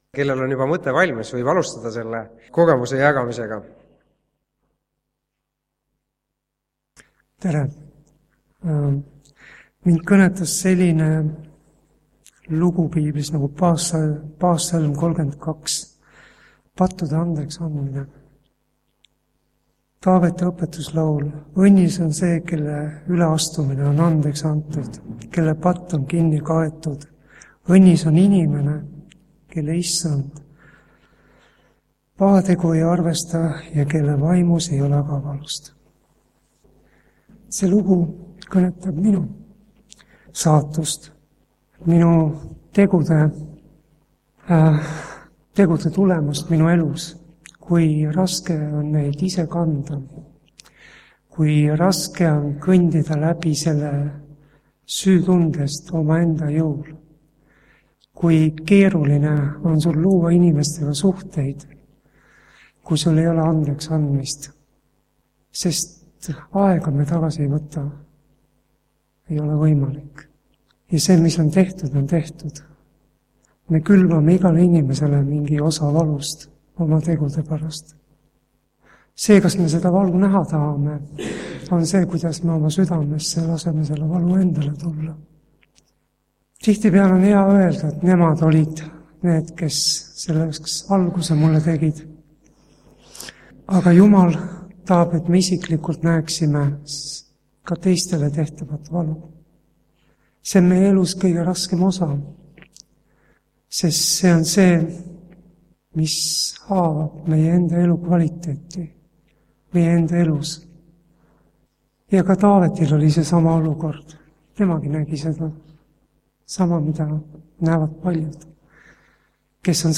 Täna on kogudusel külas Saaremaalt Kuressaare kogudus ja koos toimub siin